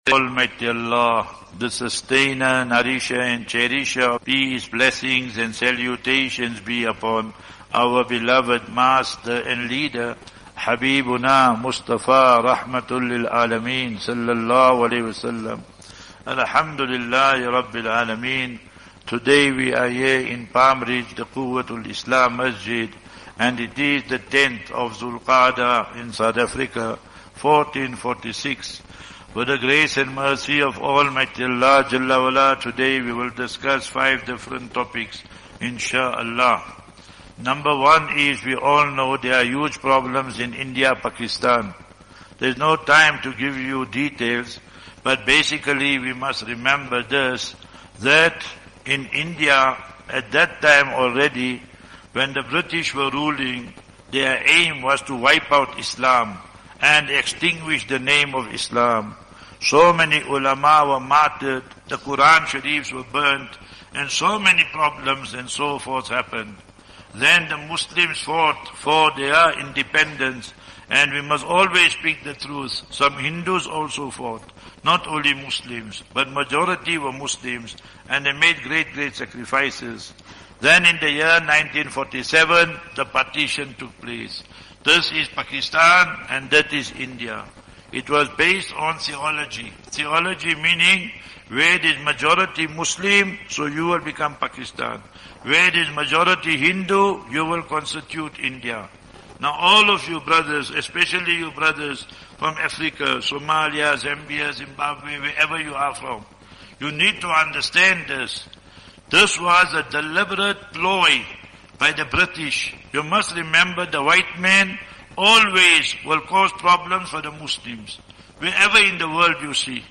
9 May 09 May 2025 - Jumu'ah Lecture in Masjid Quwwatul Islam - Palmridge